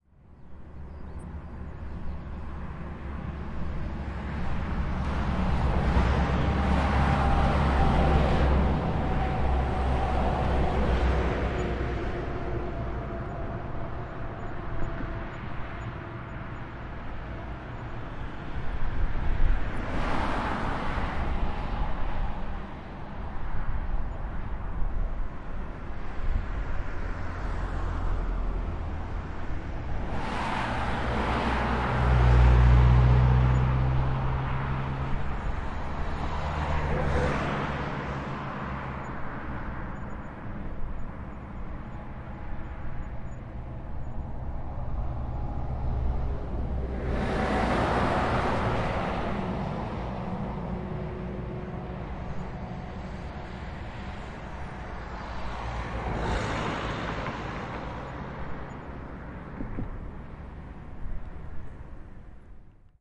街道的声音汽车
描述：街道声音交通车
Tag: 道路 音景 环境 汽车 交通 街道 氛围 环境 汽车 现场记录 一般噪音 噪音